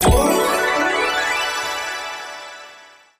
Appear_Scatter_Win_Sound.mp3